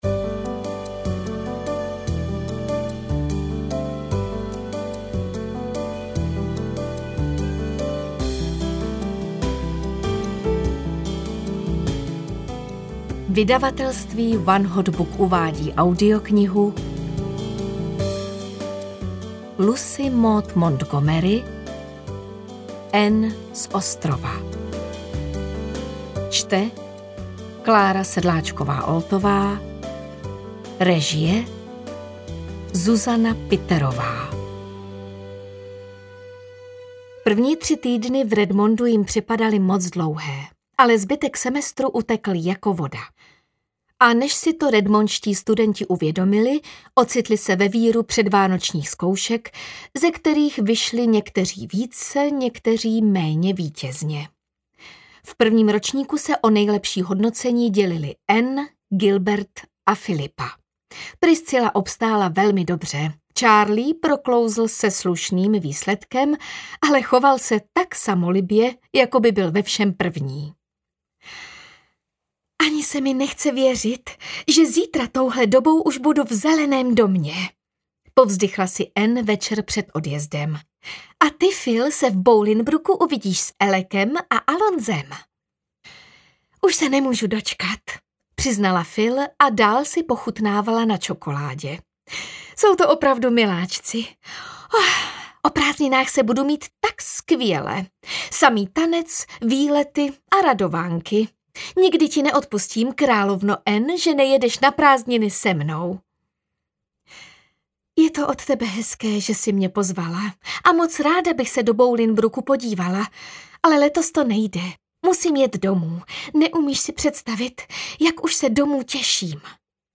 Anne z ostrova audiokniha
Ukázka z knihy